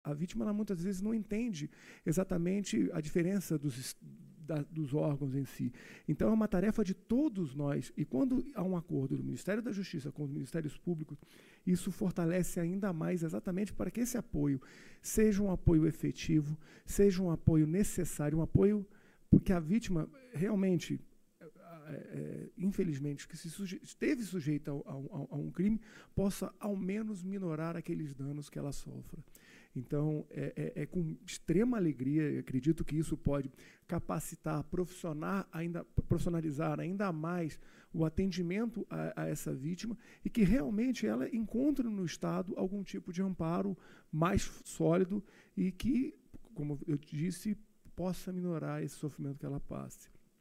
Georges Seigneur, procurador-geral de Justiça do DF, fala sobre o impacto da integração para um melhor atendimento à vítima de crimes — Ministério da Justiça e Segurança Pública